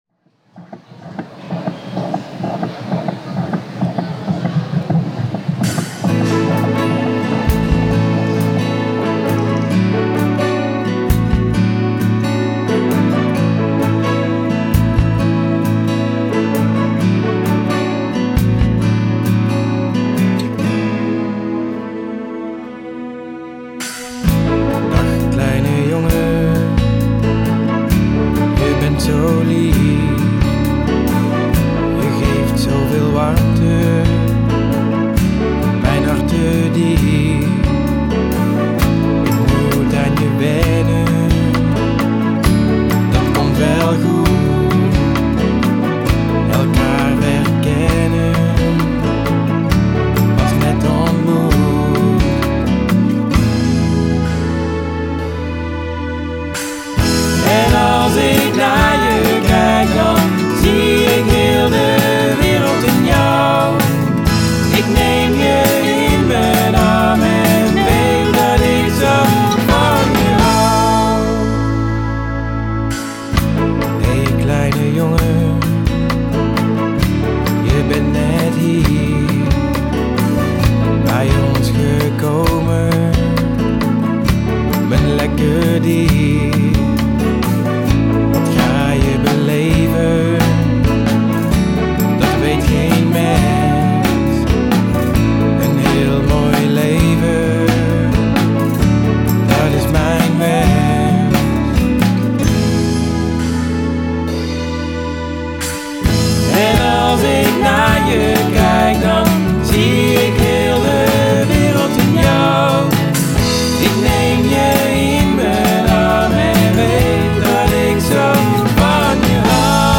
Trombone & tweede stemmen